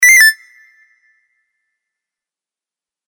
messagealert2.mp3